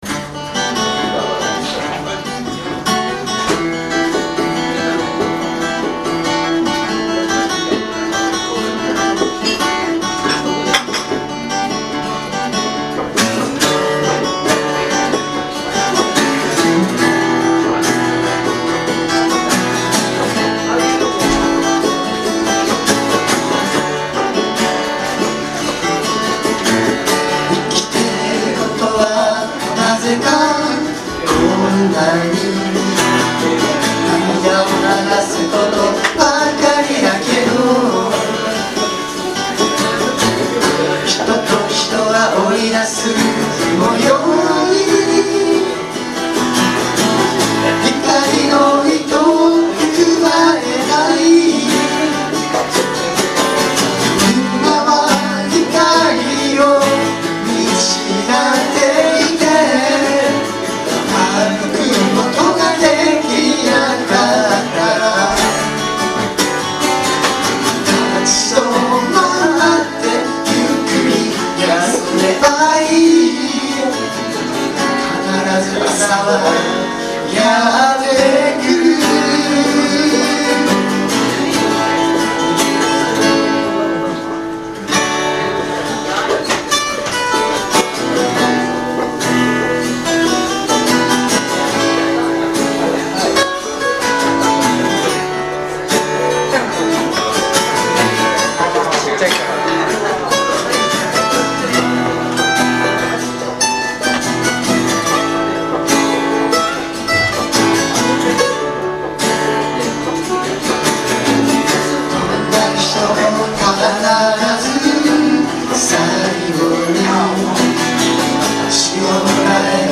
Bluegrass style Folk group
Key of D